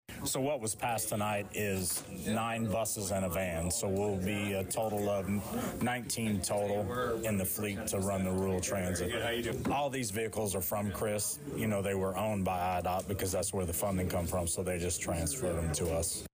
During Tuesday evening’s (Feb 17th) Danville City Council meeting, an intergovernmental agreement was approved allowing Danville Mass Transit to receive additional vehicles for this planned “Danville Rural Transit.”